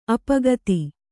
♪ apagati